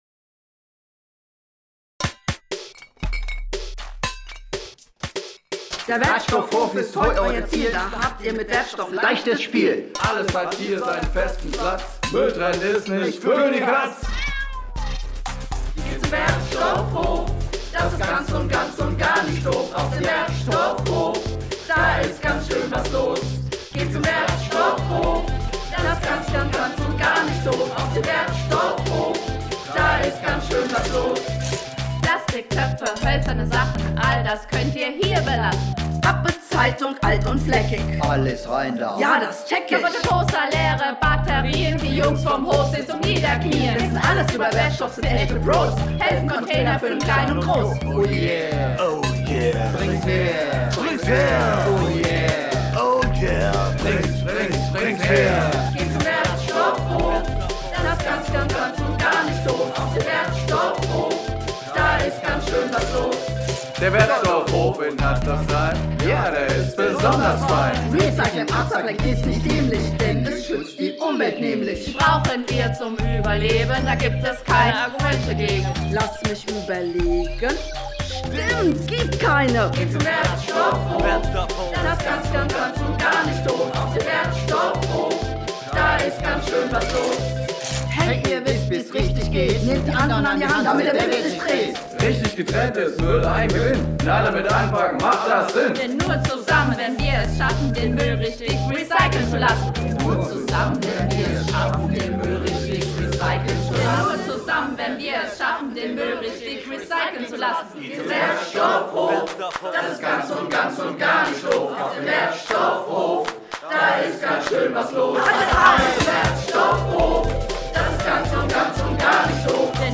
Das Team der Stadtwerke hat im Rahmen der Aktion Sauberhaftes Hattersheim einen „Wertstoff-Rap“ aufgenommen. Darin werben die Mitarbeiterinnen und Mitarbeiter der Stadtwerke dafür, mit Müll sorgfältig und achtsam umzugehen. Auf die Themen Recycling, Upcycling und den richtigen Umgang mit Wertstoffen wird im Song eingegangen - und dass es nur gemeinsam möglich ist, Positives zu bewirken.
Wertstoff_Rap_Hattersheim.mp3